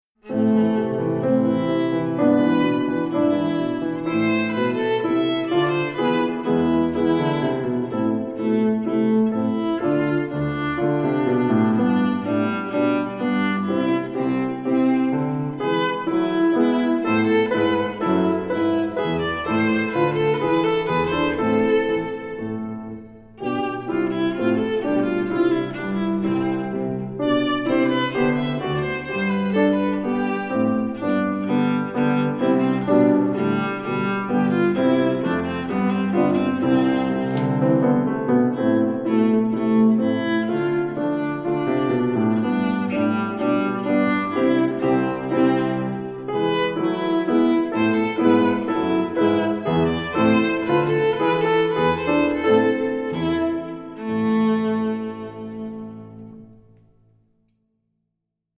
Voicing: Viola